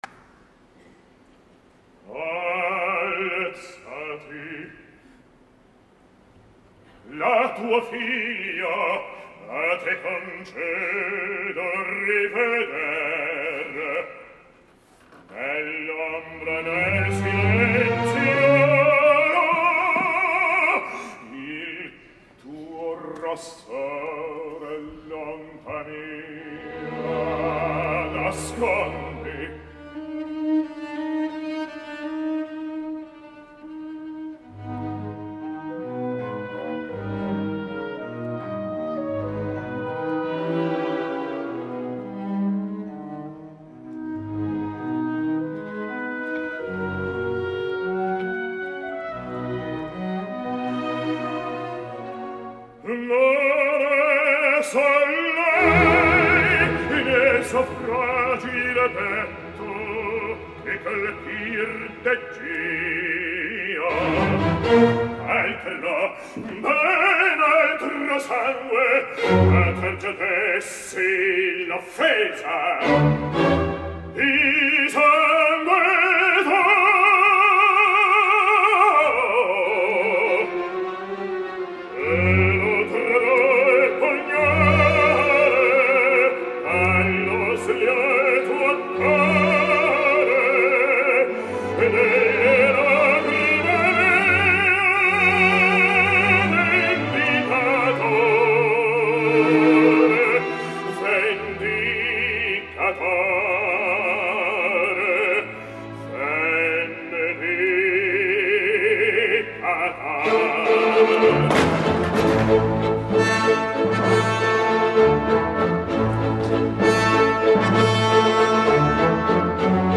Ja sabeu el que opino del baríton Dmitri Hvorostovsky, una veu també magnífica al servei d’un egòlatra amb problemes de respiració i bastant monolític.
Renato (Count Ankarström)……….Dmitri Hvorostovsky